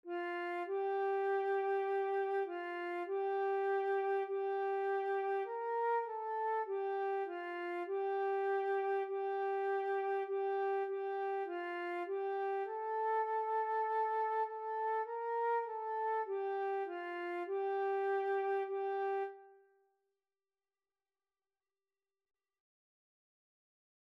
4/4 (View more 4/4 Music)
F5-Bb5
Flute  (View more Beginners Flute Music)
Classical (View more Classical Flute Music)